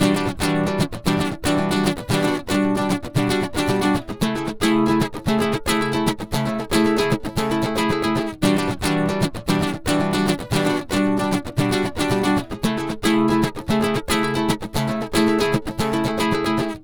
Ala Brzl 3 Nyln Gtrs-E.wav